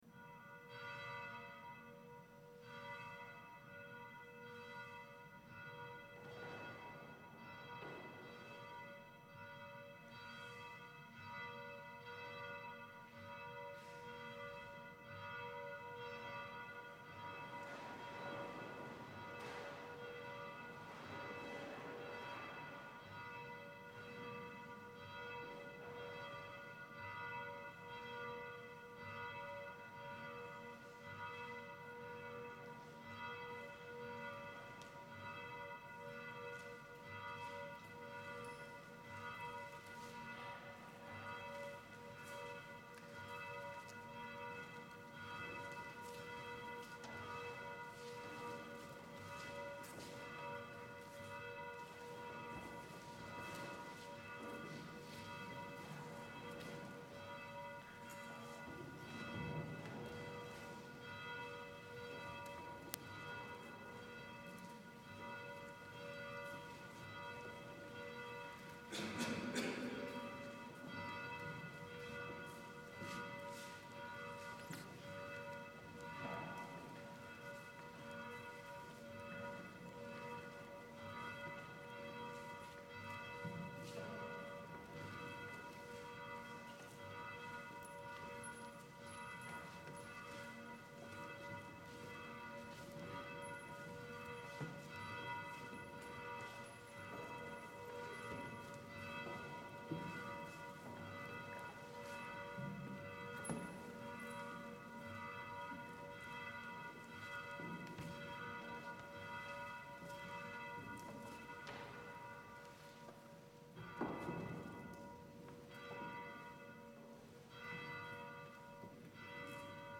Gregorian
Chanting Monk